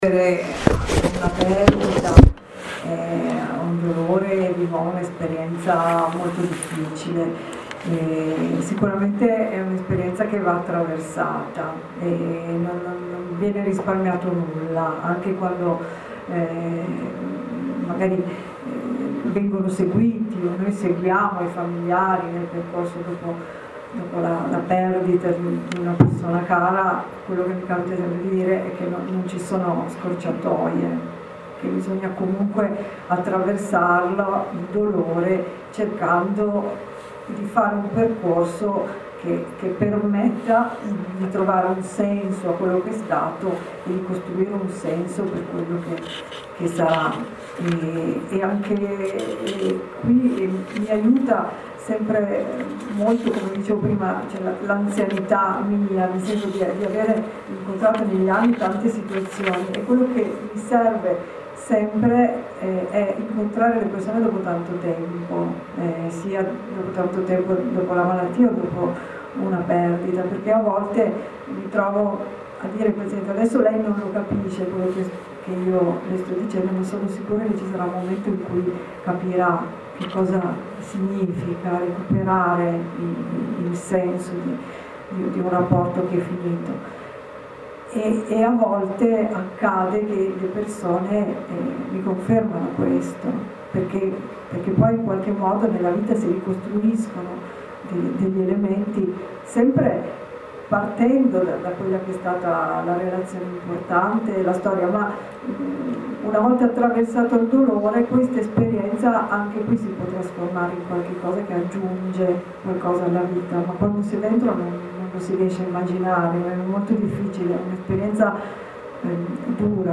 LA VITA CHE NON HO SCELTO, incontro-dibattito organizzato dalla associazione ACCANTO, Como 11 aprile 2013
Repliche dei relatori